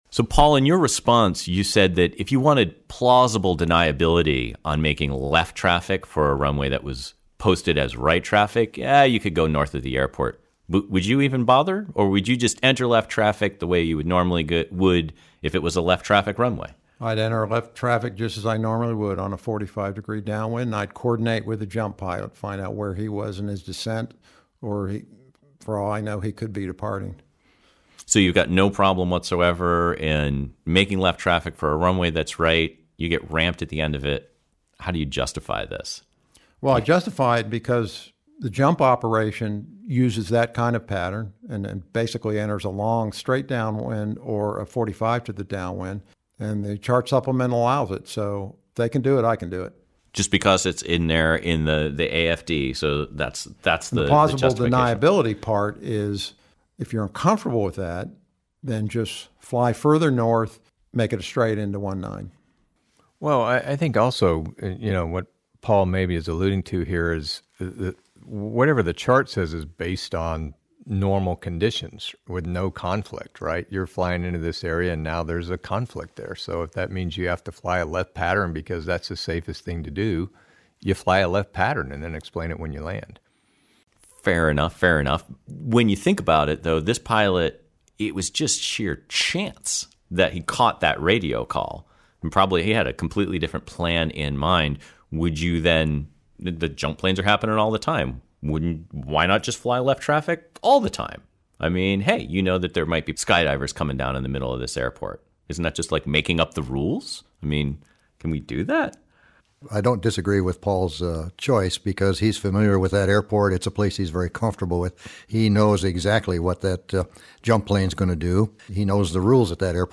Jumpers_away_roundtable.mp3